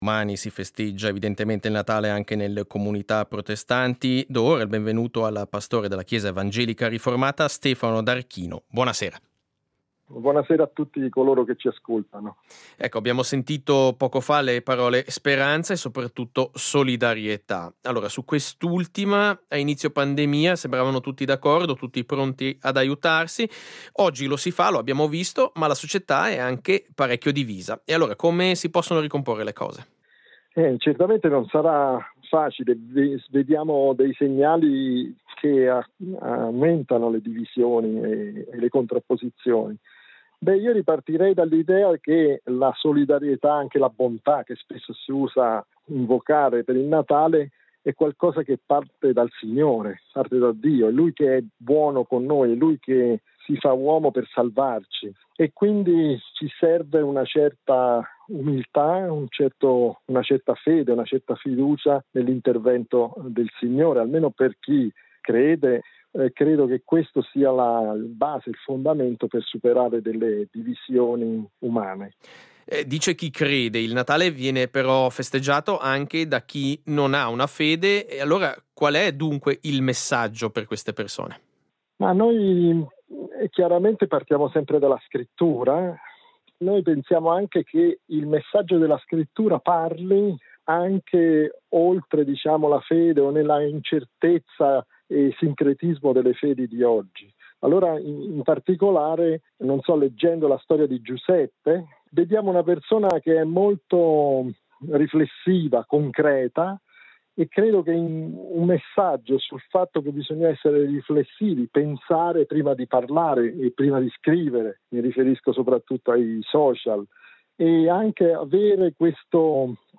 SEIDISERA del 24.12.21: Intervista al pastore evangelico